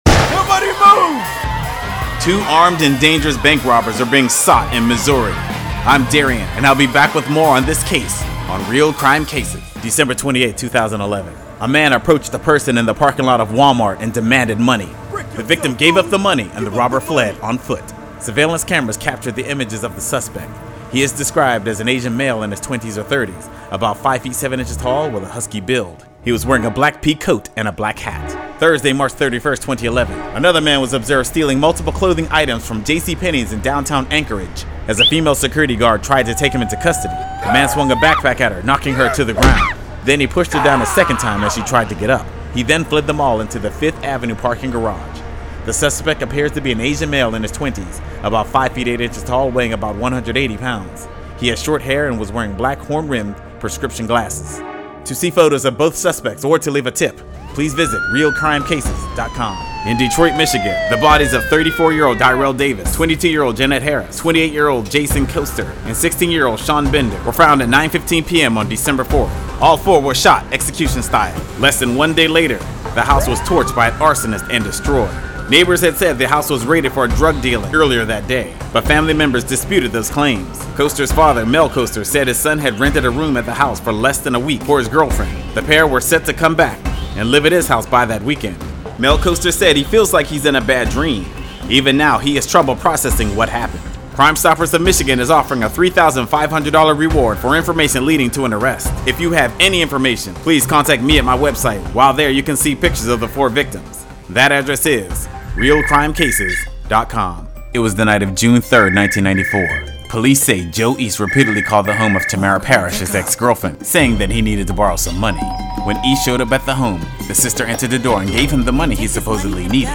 “Real Crime Cases” is a daily two-minute radio feature profiling missing person, wanted fugitives and unsolved crimes.